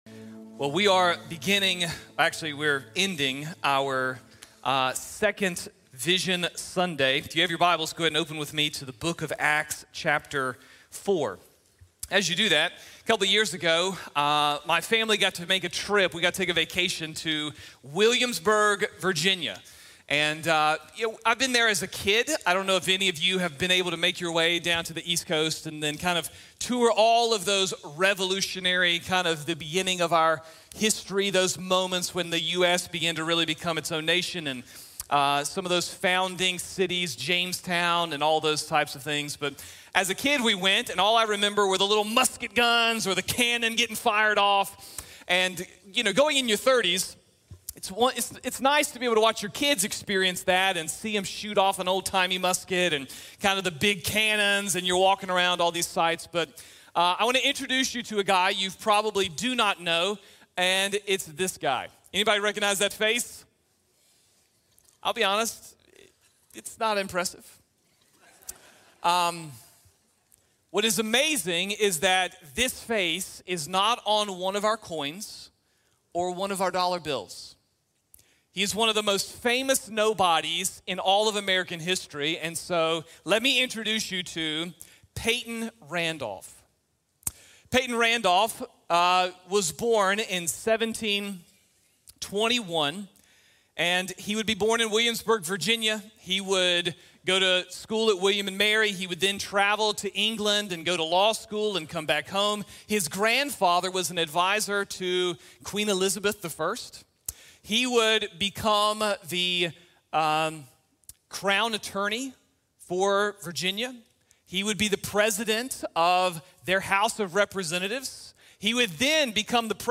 Be a Barnabas | Sermon | Grace Bible Church